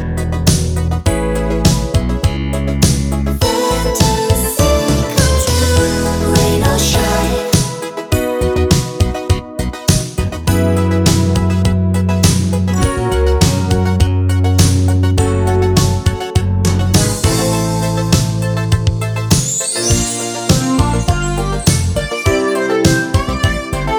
no sax solo Pop (1980s) 4:03 Buy £1.50